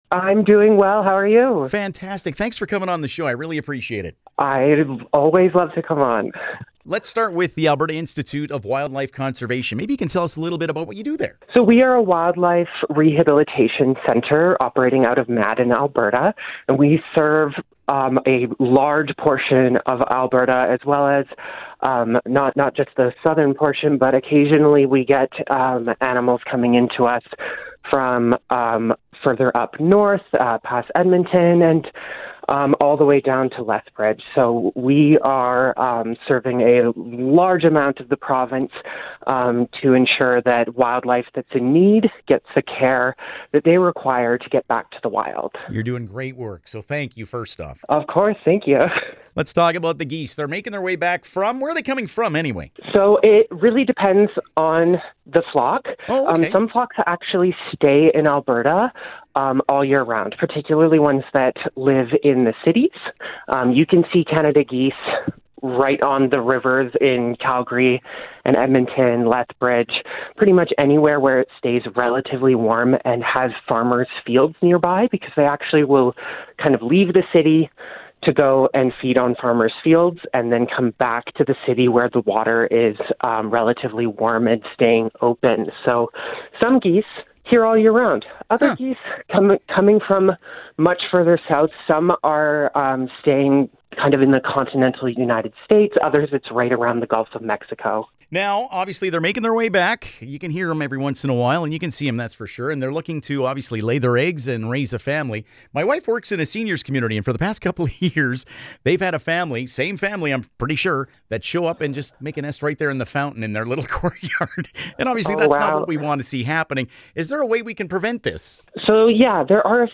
geese-int.mp3